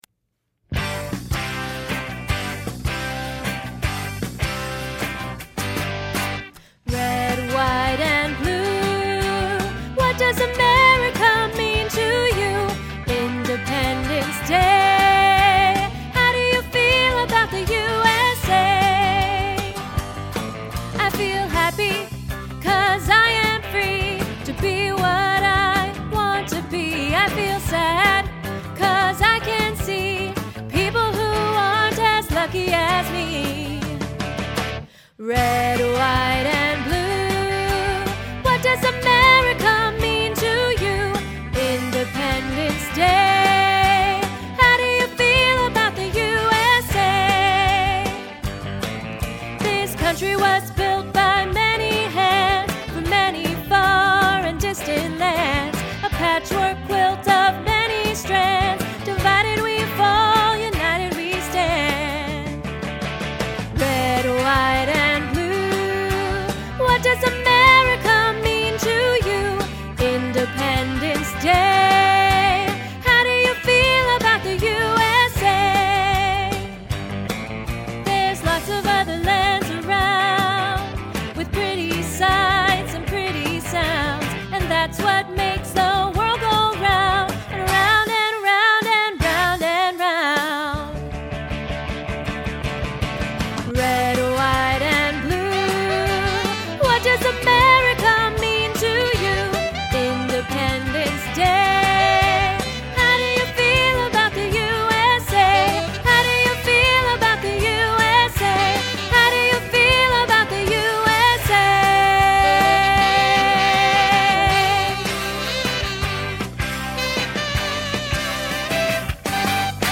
Red White and Blue - JA Vocal Cut Version.mp3